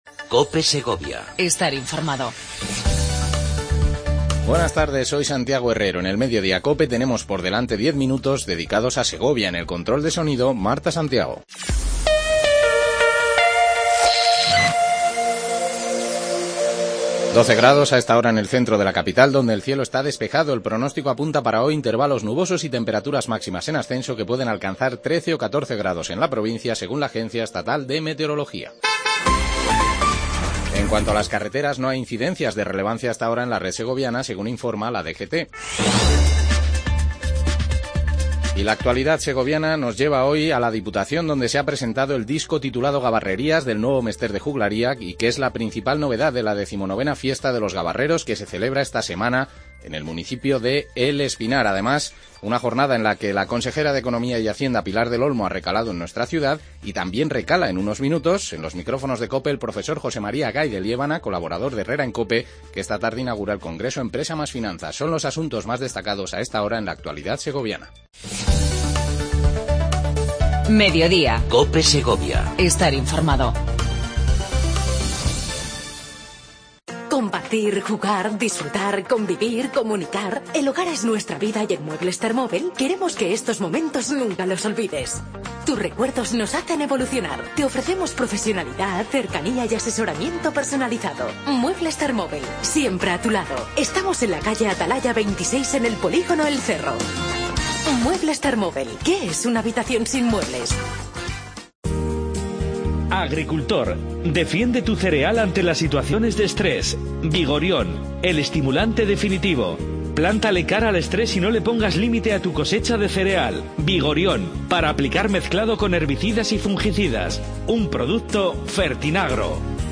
Redacción digital Madrid - Publicado el 01 mar 2017, 13:47 - Actualizado 18 mar 2023, 17:04 1 min lectura Descargar Facebook Twitter Whatsapp Telegram Enviar por email Copiar enlace Avance de las noticias más destacadas del día. Entrevista con El Profesor José María Gay De Liébana, colaborador de Herrera en Cope.